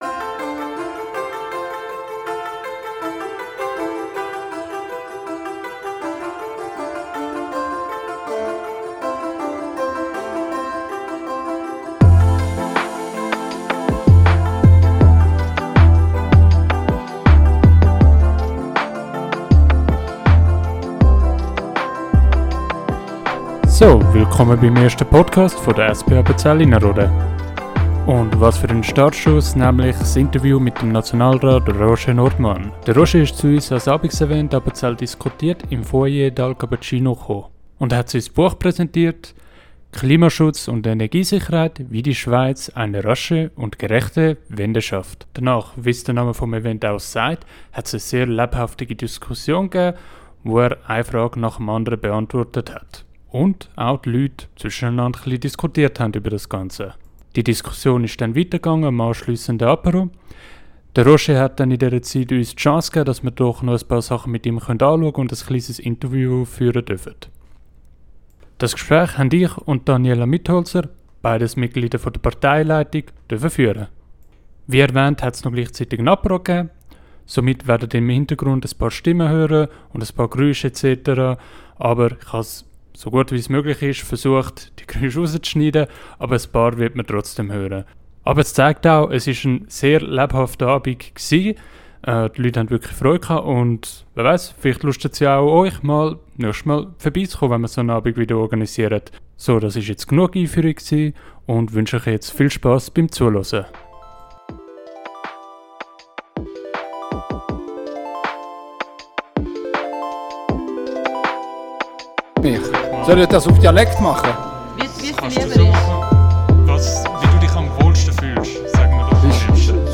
Vortrag von Roger Nordmann